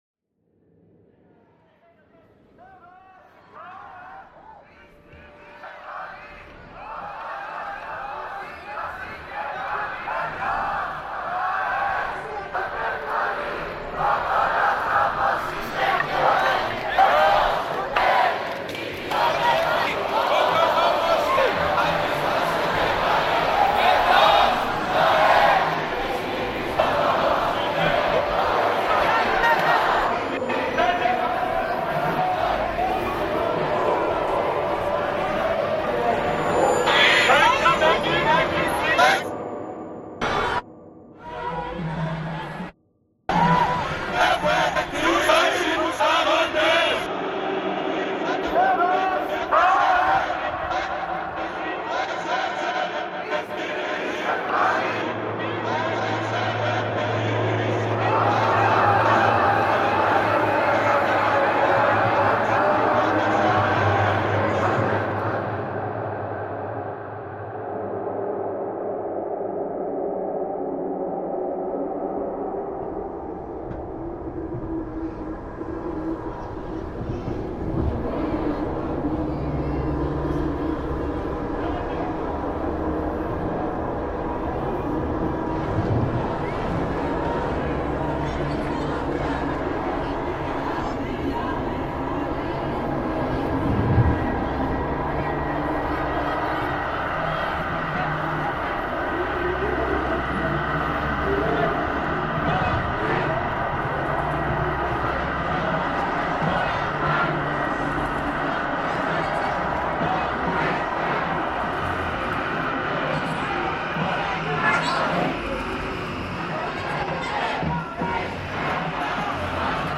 Athens protest reimagined